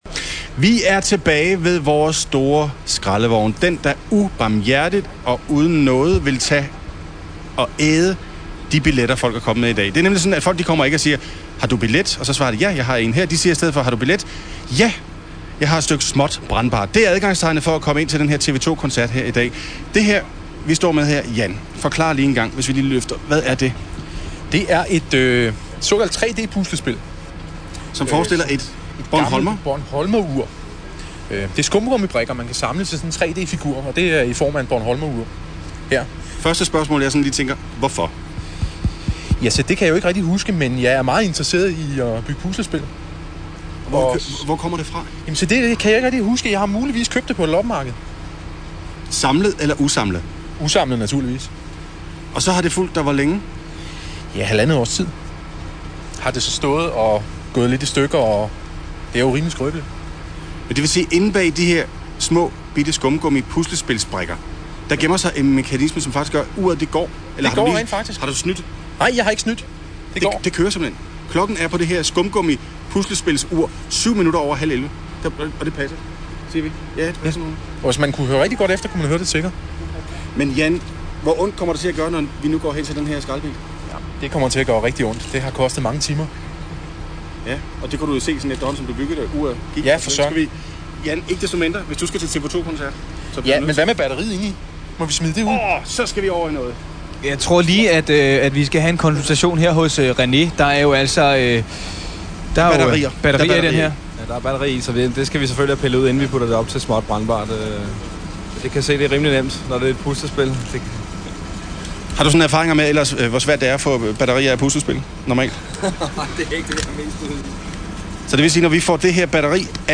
Jeg har lige siddet og ryddet op i mit lydbibliotek på computeren, og her faldt jeg over en gammel “Strax”-radioudsendelse fra 2002, hvor jeg havde den tvivlsomme ære at deltage i et fuldkommen tåbeligt interview, og det fortjener et blogindlæg.